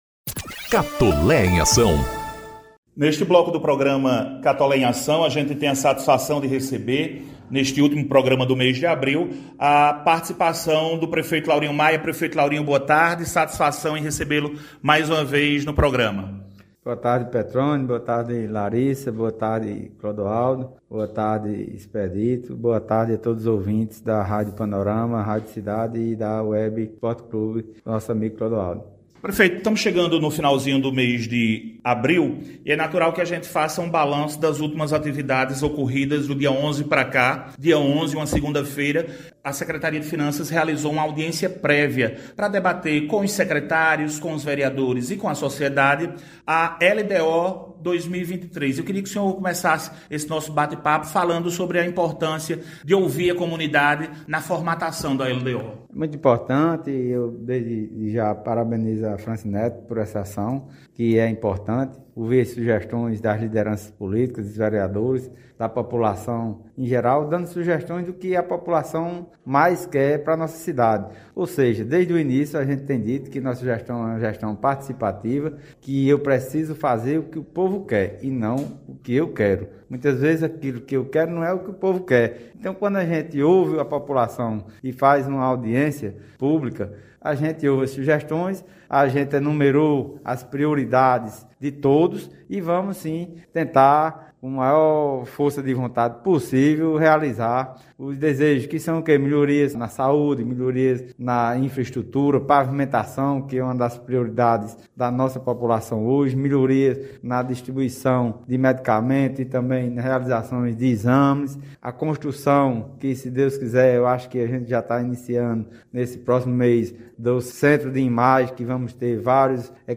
CATOLÉ DO ROCHA 187 ANOS: Prefeito Laurinho anuncia ações, serviços e obras. Ouça a entrevista na íntegra!
Participando do Programa “Catolé em Ação”, sexta-feira (29/04), o prefeito Laurinho Maia fez uma avaliação da Audiência Prévia da LDO-2023, posse da equipe da secretaria de planejamento e da reunião com a Comissão Permanente de Concursos da UEPB (Universidade Estadual da Paraíba).